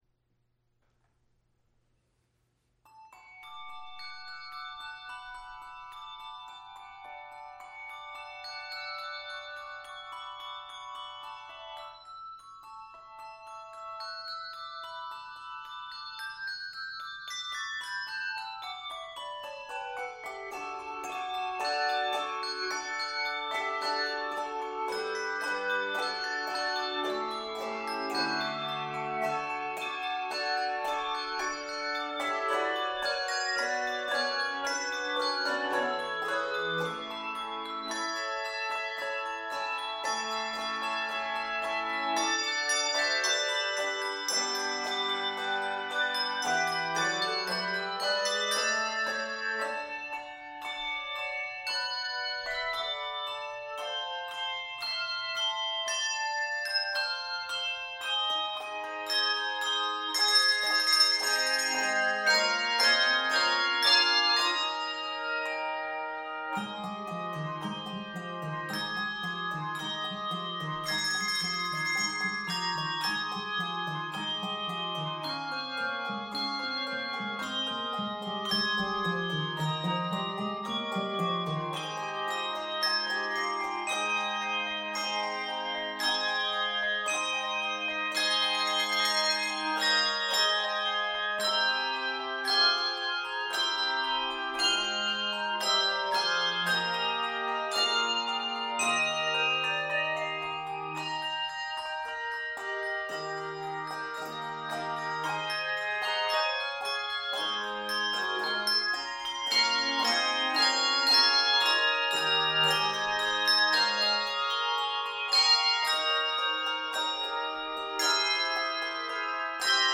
running eighth-note patterns and unexpected key changes
Keys of D Major and Ab Major.